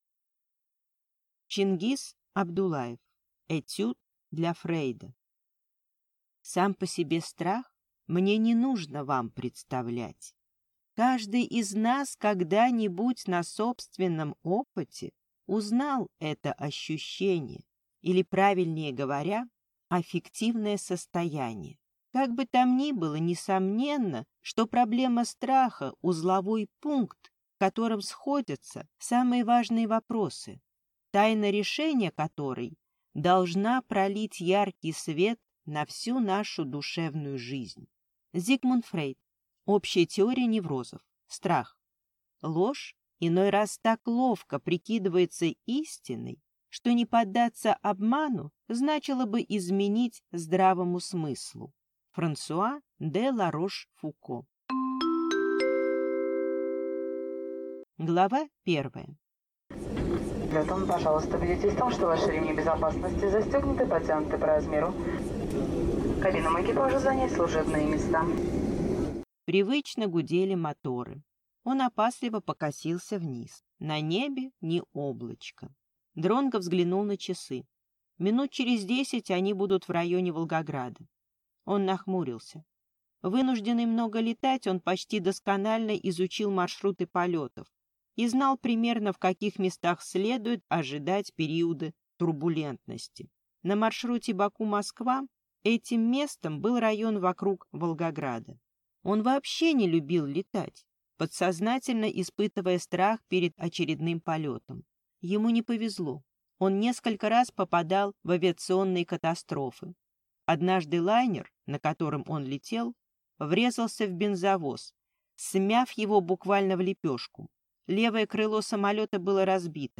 Аудиокнига Этюд для Фрейда | Библиотека аудиокниг